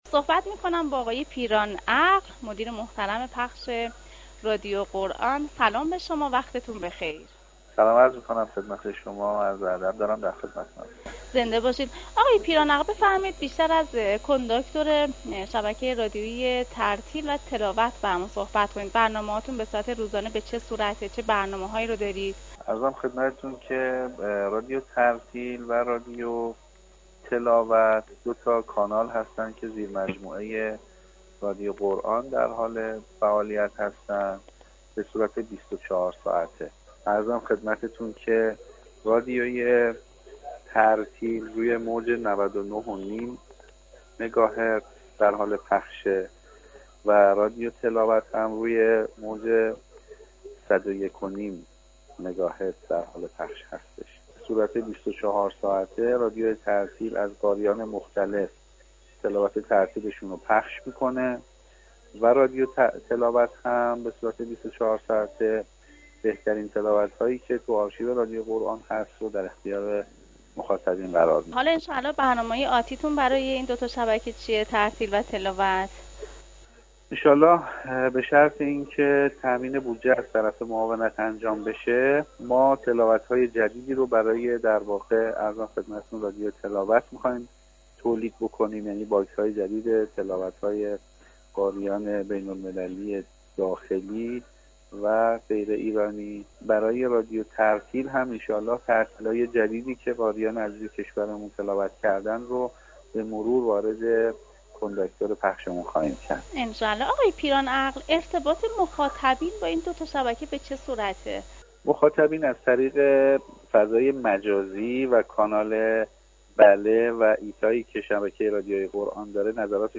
طی گفتگویی تلفنی از برنامه های رادیو تلاوت و ترتیل می گوید.